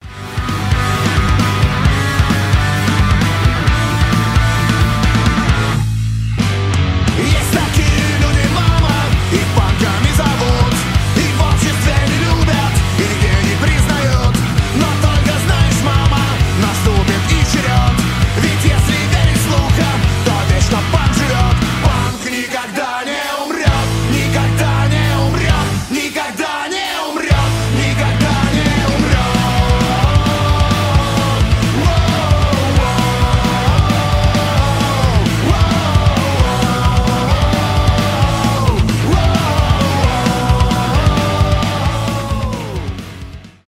панк-рок